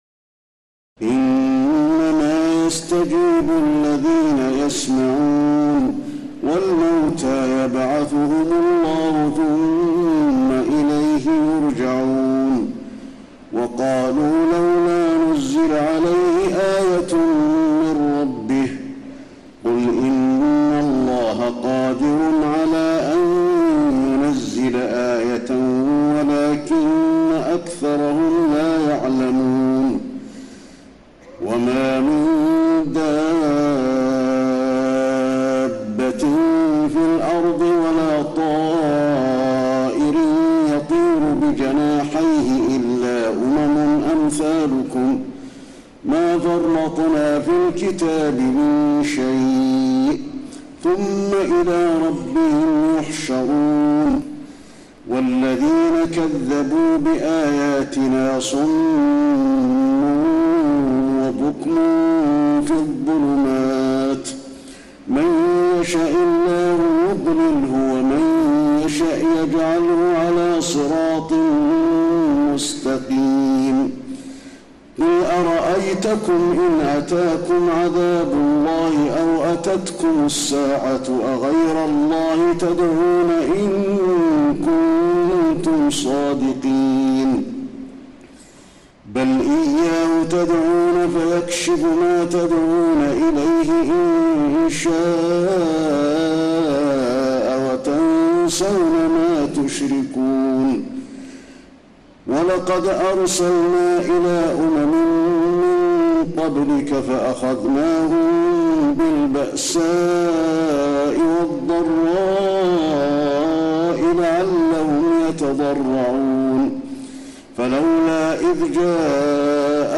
تراويح الليلة السابعة رمضان 1432هـ من سورة الأنعام (36-110) Taraweeh 7 st night Ramadan 1432H from Surah Al-An’aam > تراويح الحرم النبوي عام 1432 🕌 > التراويح - تلاوات الحرمين